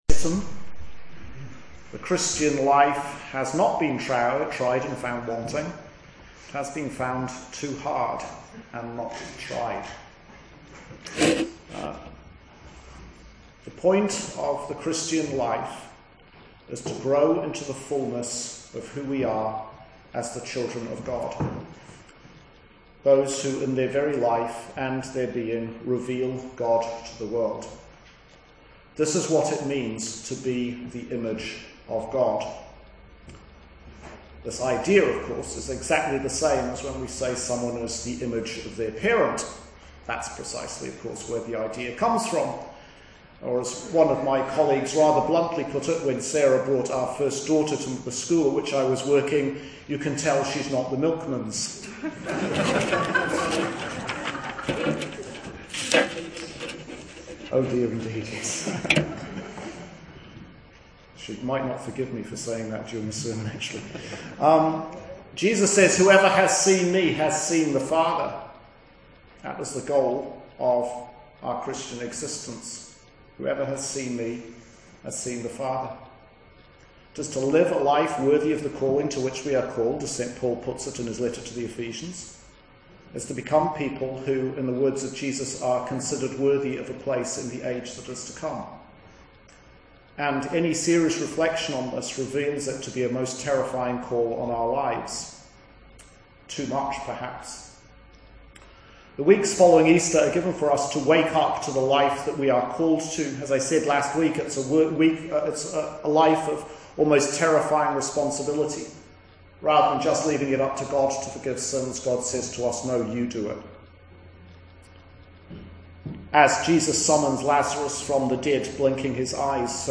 Easter 3 Sermon – Of climbing unroped and The Life of the Age to Come